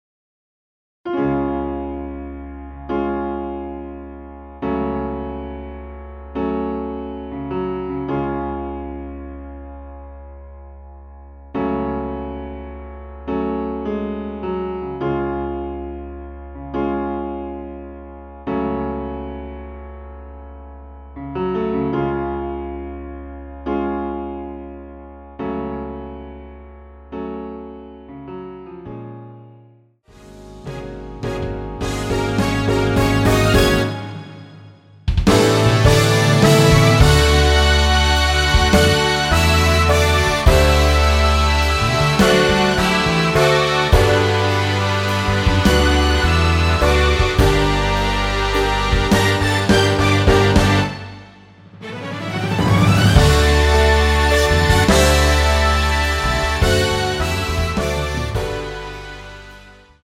Db
◈ 곡명 옆 (-1)은 반음 내림, (+1)은 반음 올림 입니다.
앞부분30초, 뒷부분30초씩 편집해서 올려 드리고 있습니다.
중간에 음이 끈어지고 다시 나오는 이유는